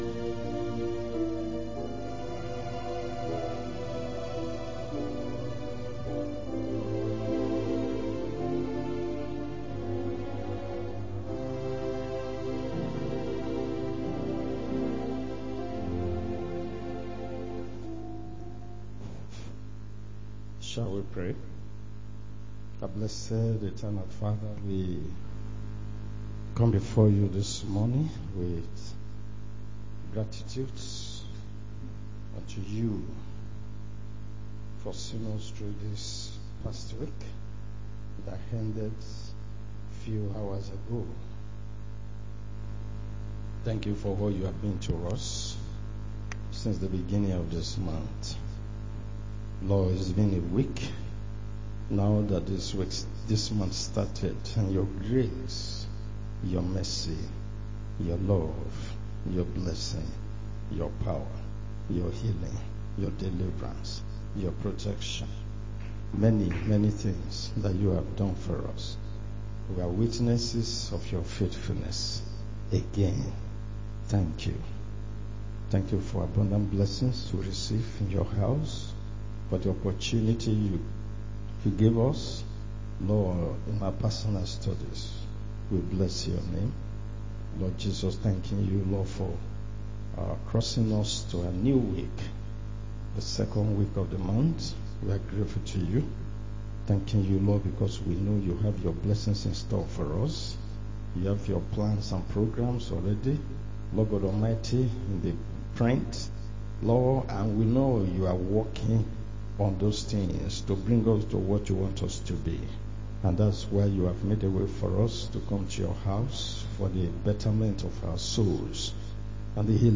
Sunday School Class